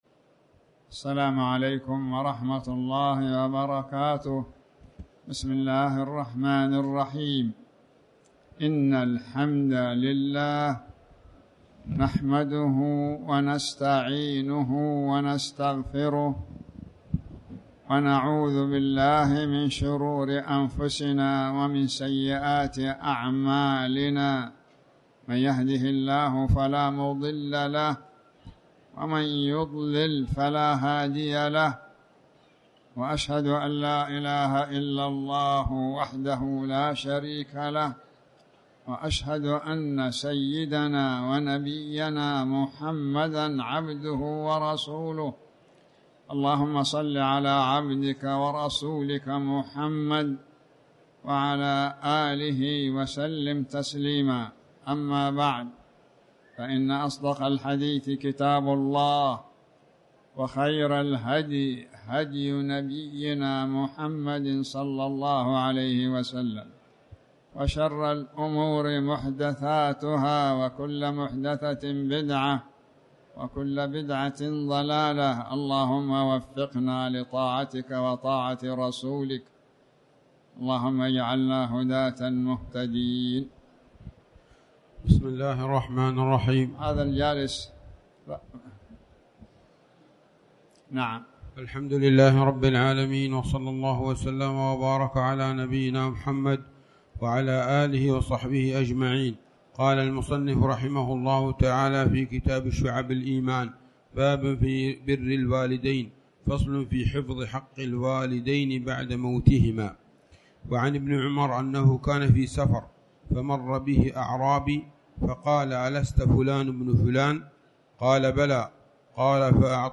تاريخ النشر ١٠ ربيع الأول ١٤٤٠ هـ المكان: المسجد الحرام الشيخ